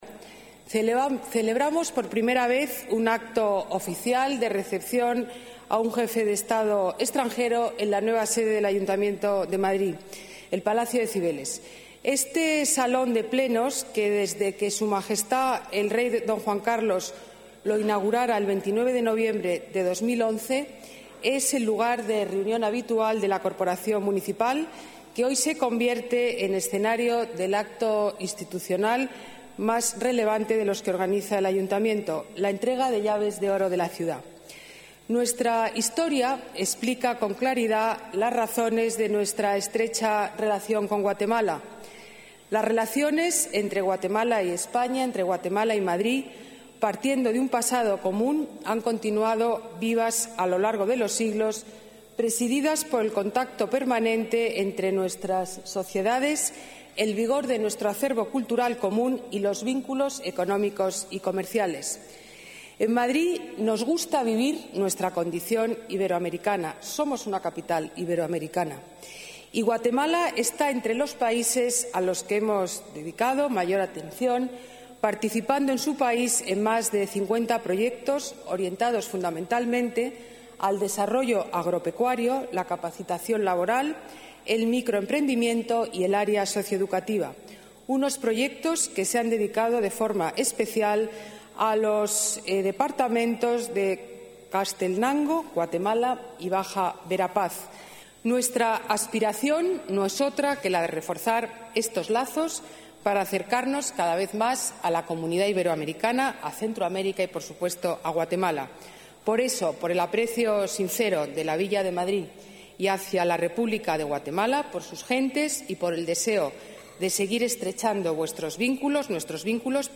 Guatemala y Madrid siempre se han sentido cerca pero hoy ,por primera vez, su presidente, Otto Pérez Molina, ha pisado el nuevo Salón de Plenos de Cibeles y se ha convertido en el primer presidente
Nueva ventana:Extracto del discurso de Ana Botella, alcaldesa de Madrid